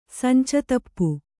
♪ sanca tappu